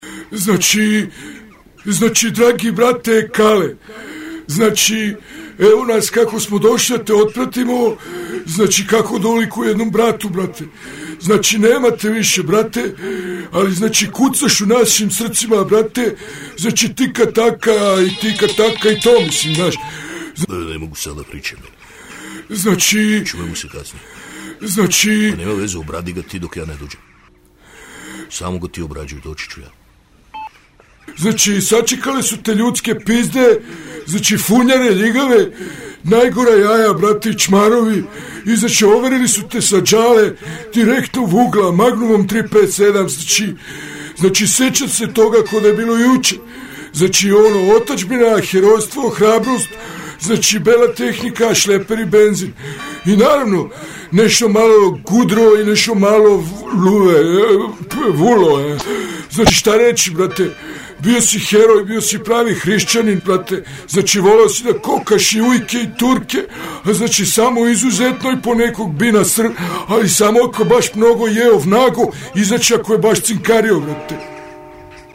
Zemunska sahrana.mp3